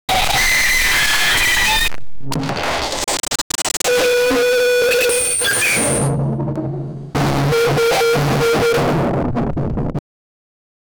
catchy-rizz-sound-in-a-viral-meme-n6y4jjfn.wav